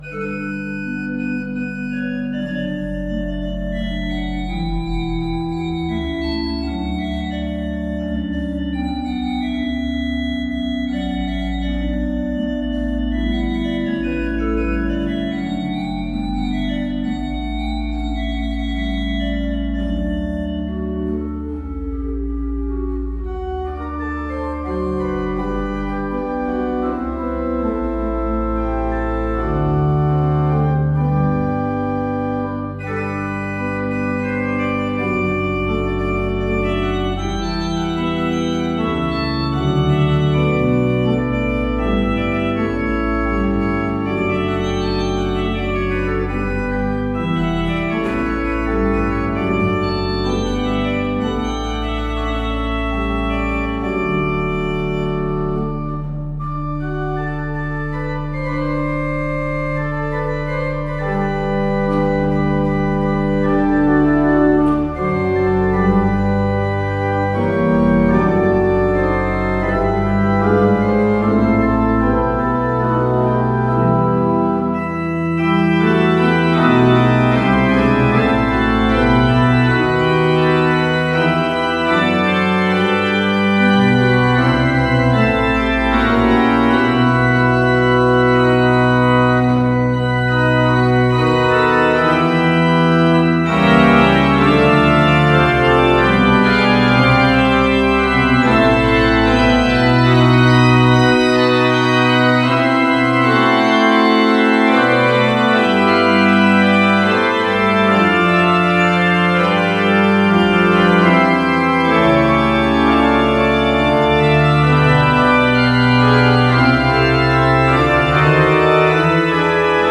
kostel sv. Jana Nepomuckého
Nahrávky varhan:
Vsemina, sesquialtera, principaly, pleno.mp3